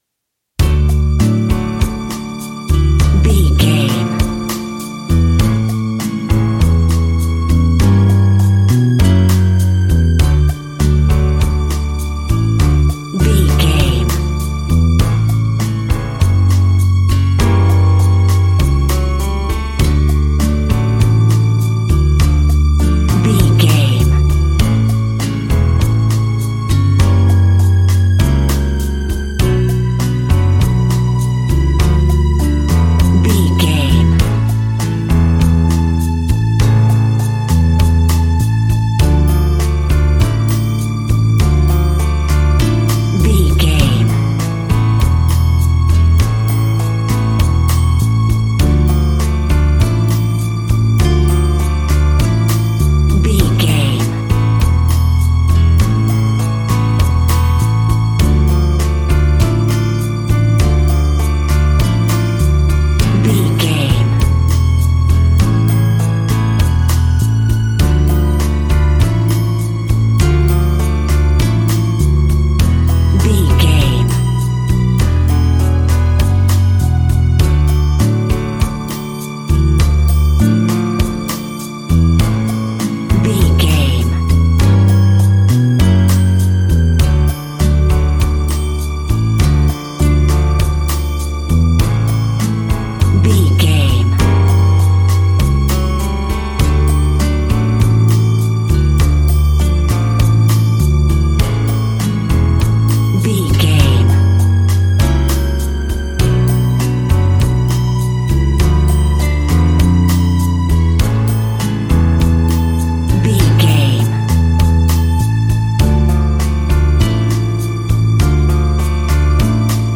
An exotic and colorful piece of Espanic and Latin music.
Ionian/Major
Slow
romantic
maracas
percussion spanish guitar